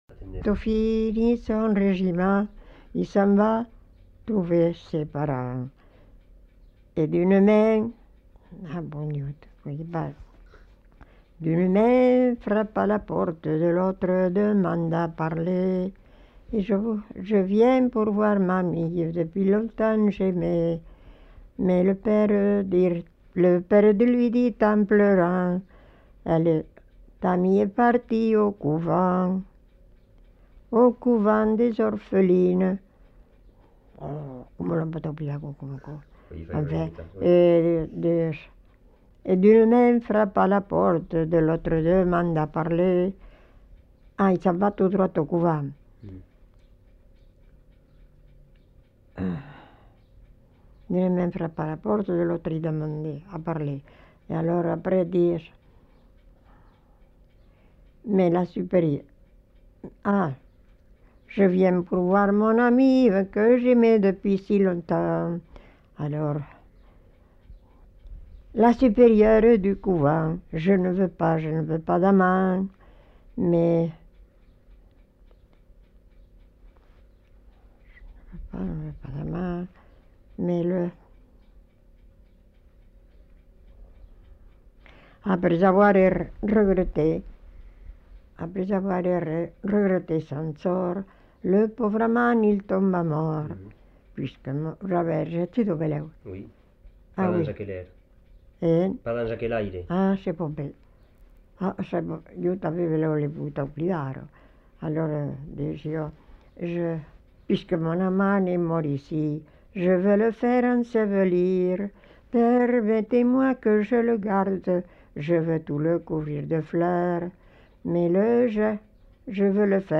Aire culturelle : Haut-Agenais
Lieu : Fumel
Genre : chant
Effectif : 1
Type de voix : voix de femme
Production du son : chanté